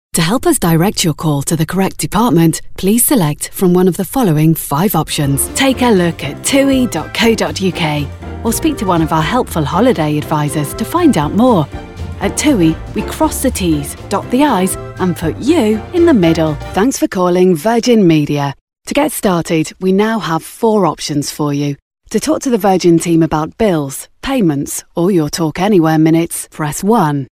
Inglés (Británico)
Llamativo, Versátil, Amable
Telefonía